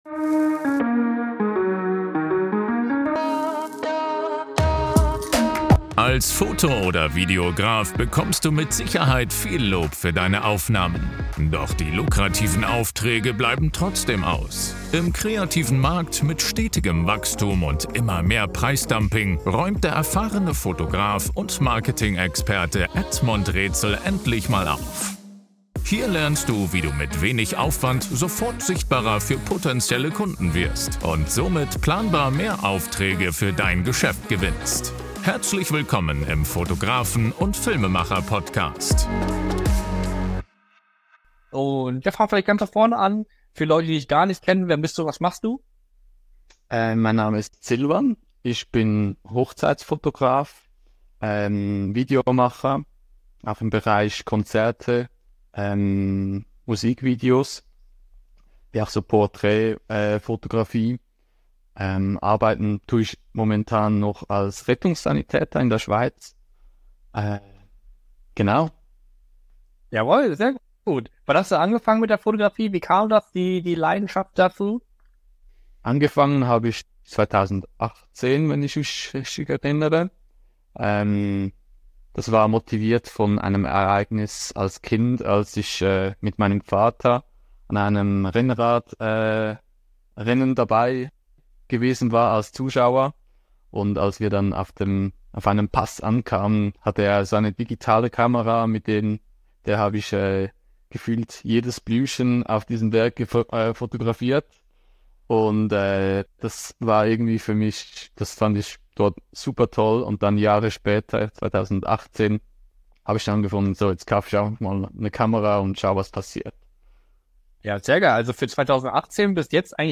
Hast du dich auch schon mal gefragt ob du JPG vs. RAW Bilder als Hochzeitsfotograf verwenden solltest? In diesem Fotografie Talk gibt es mehr Info's dazu wie du als Hochzeitsfotograf den richtigen Weg findest.